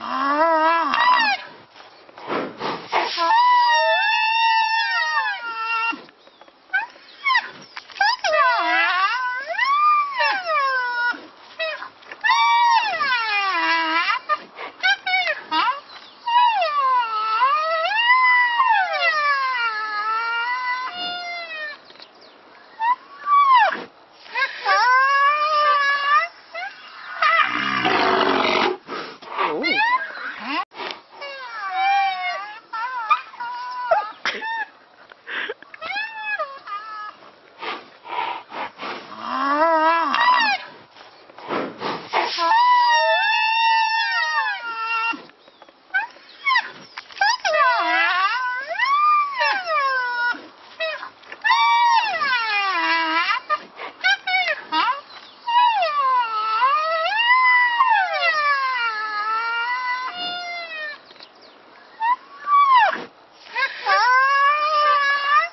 Tiếng Tê Giác kêu mp3